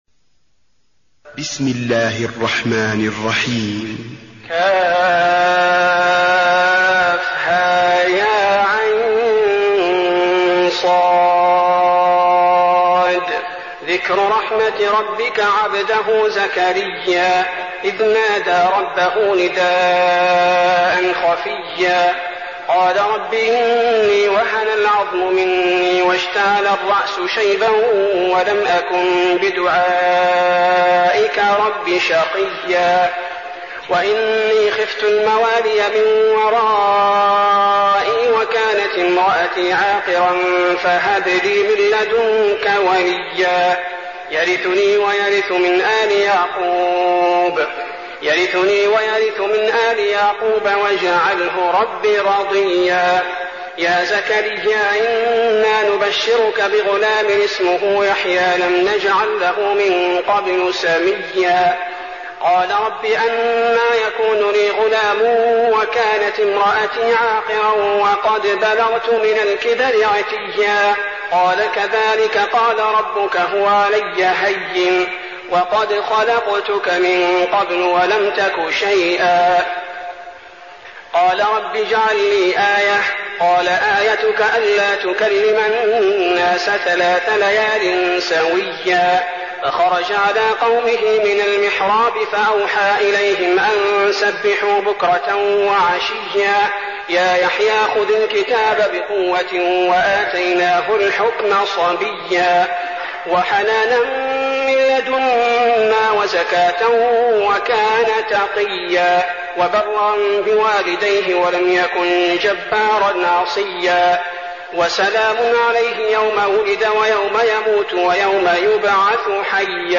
المكان: المسجد النبوي مريم